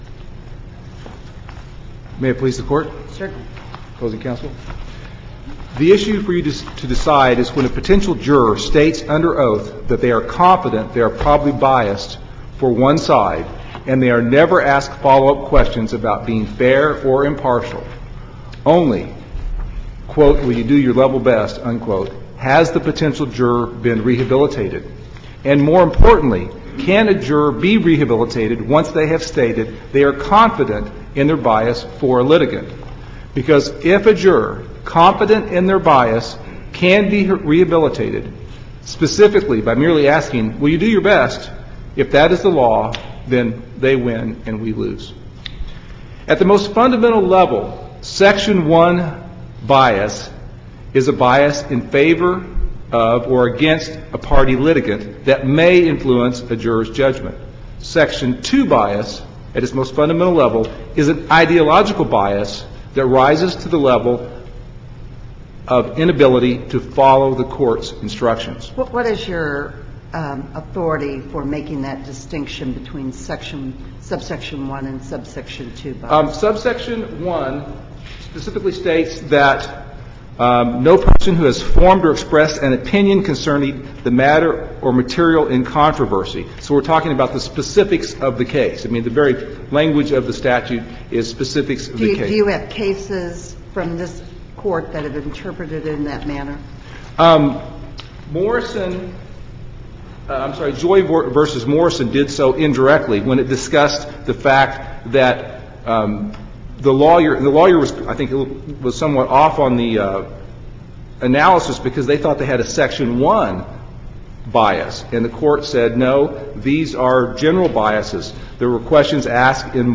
MP3 audio file of oral arguments in SC95910